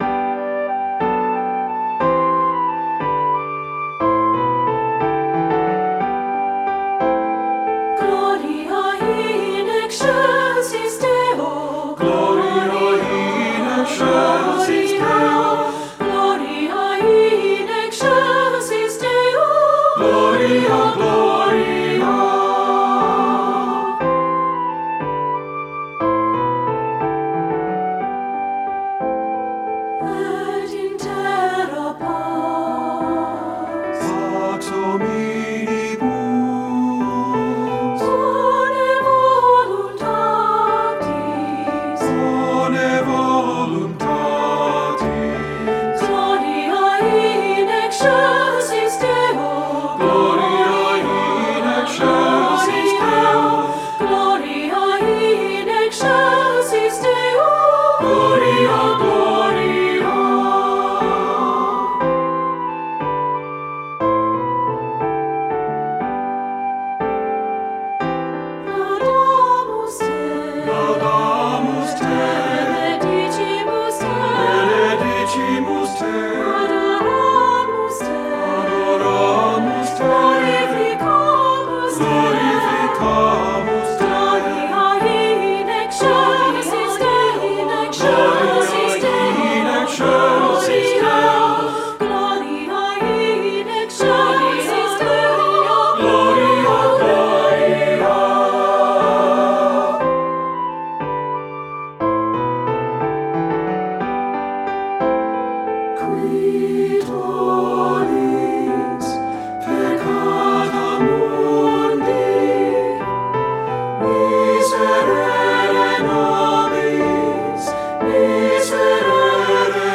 • Piano
• Flute
Studio Recording
Ensemble: Three-part Mixed Chorus
Key: G major
Tempo: q. = 60
Accompanied: Accompanied Chorus